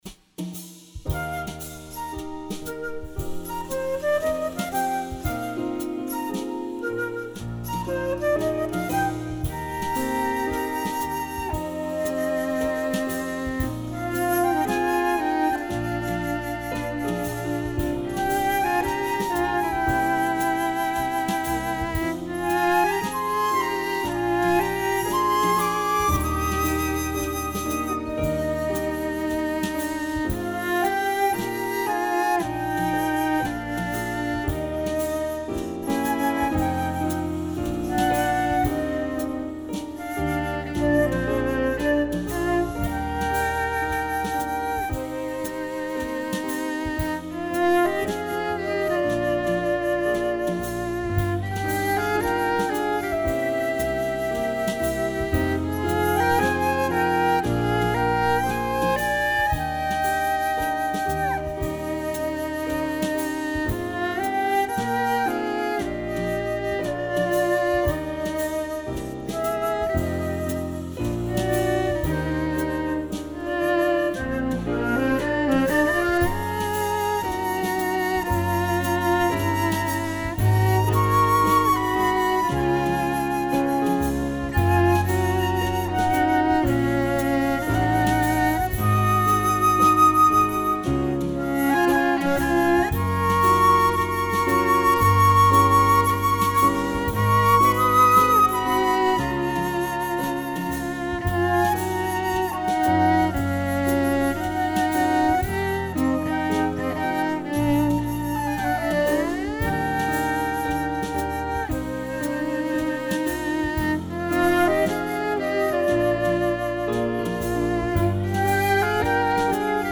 flauta, violoncello, piano y batería